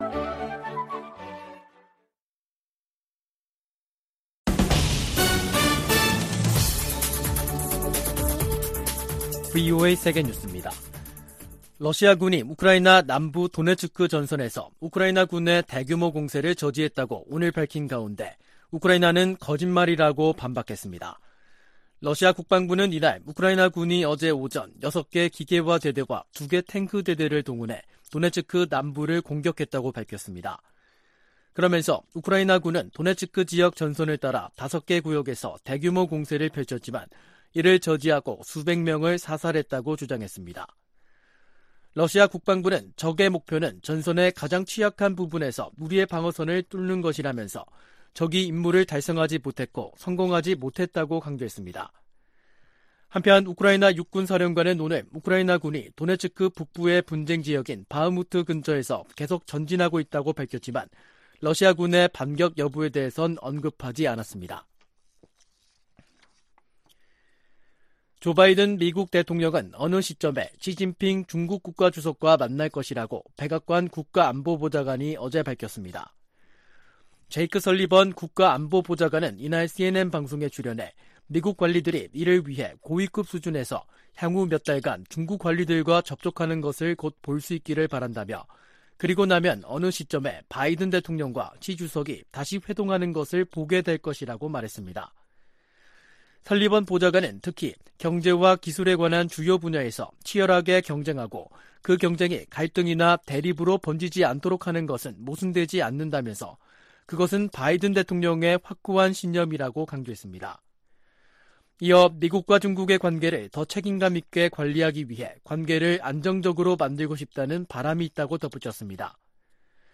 VOA 한국어 간판 뉴스 프로그램 '뉴스 투데이', 2023년 6월 5일 2부 방송입니다. 북한 서해위성발사장에서 로켓 장착용 조립 건물이 발사패드 중심부로 이동했습니다.